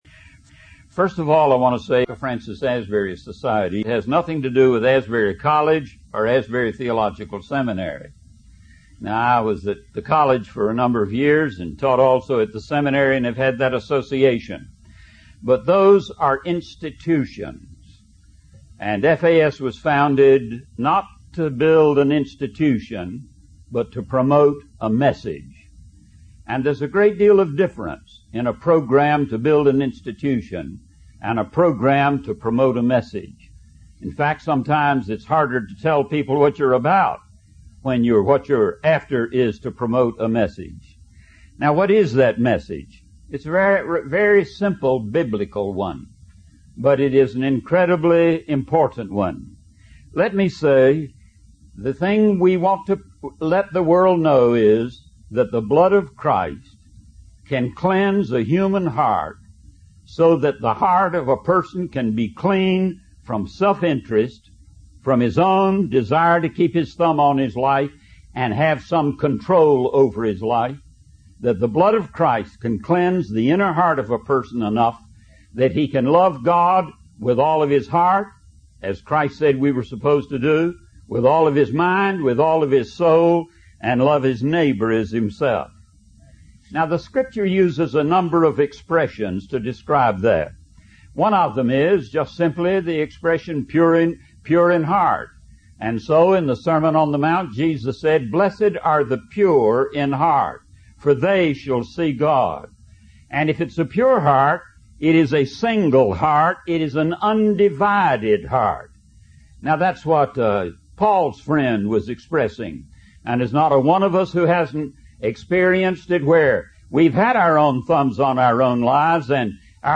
In this sermon, the speaker shares the story of A.B. Simpson, a Presbyterian pastor who had a transformative experience of being filled with the Holy Spirit.